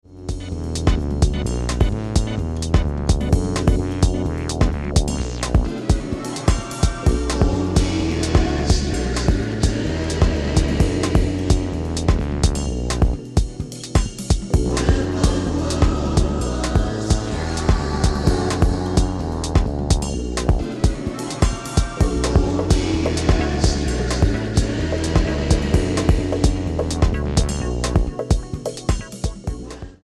an extended, percussive workout
where the haunting choral vocals take things that bit deeper